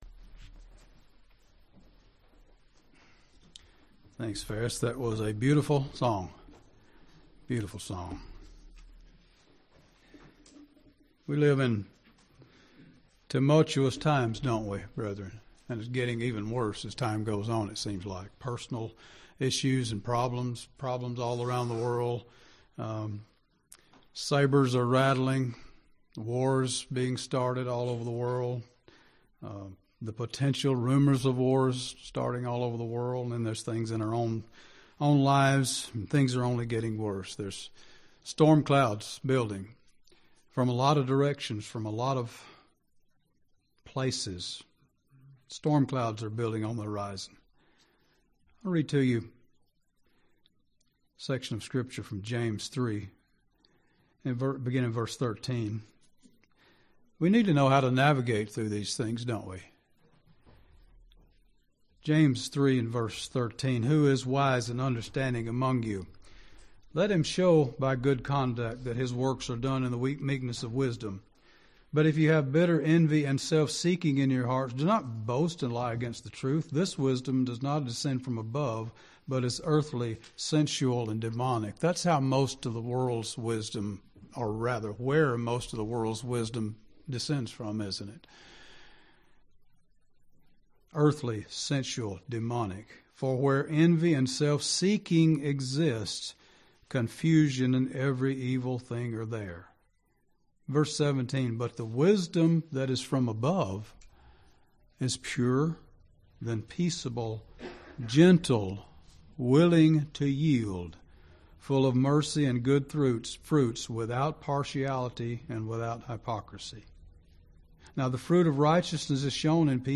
Sermons
Given in Gadsden, AL